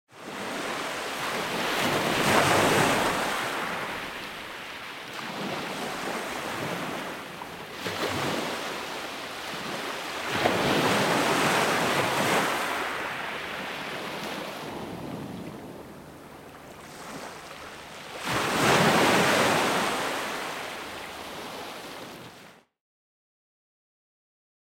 Waves